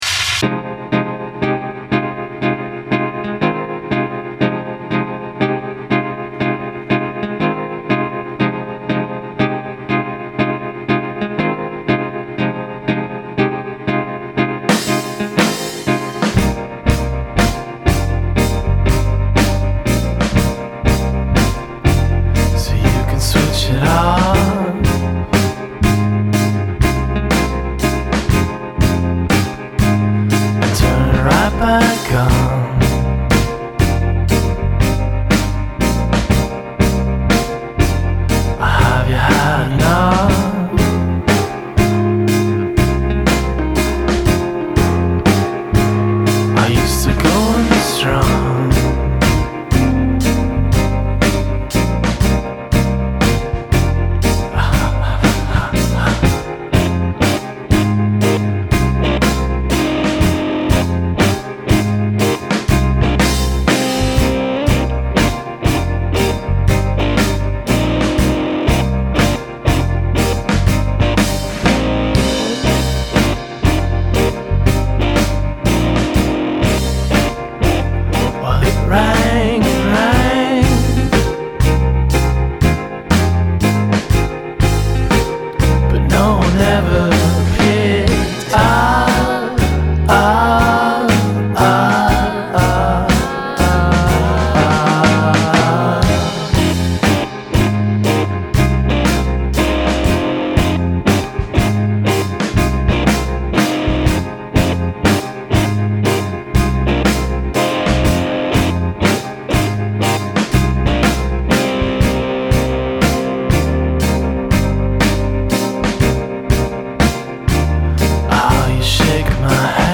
complete with the group’s trademark vocal harmonies.